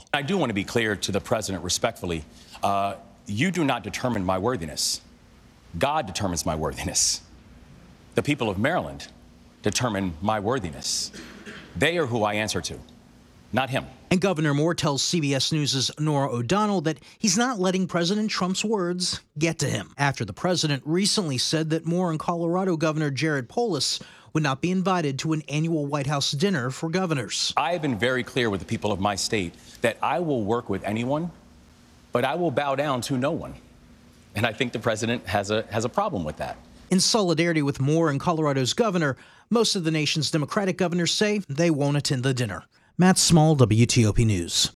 Maryland Gov. Wes Moore says he won’t bow down to anyone.